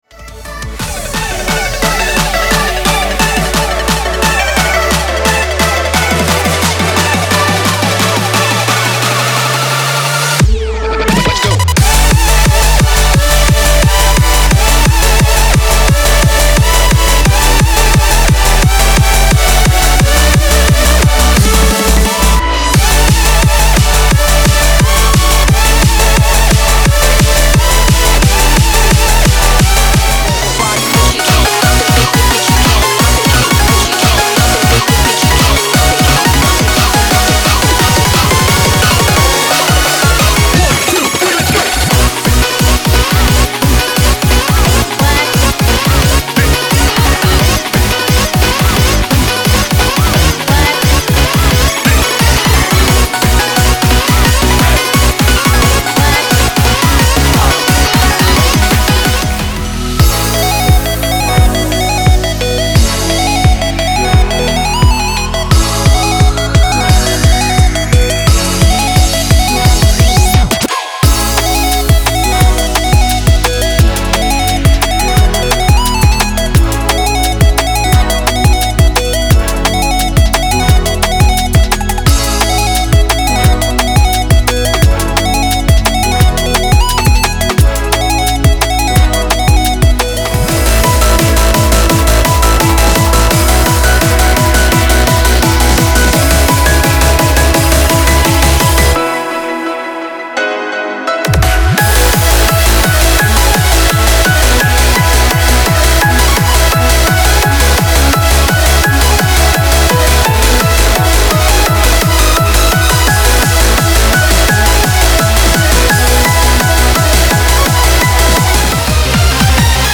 俱乐部音乐编曲CD第二弹登场！
收录了熟悉的曲目，从活动和战斗中BGM的编曲，到豪华混音师带来的精彩Remix共17首曲目。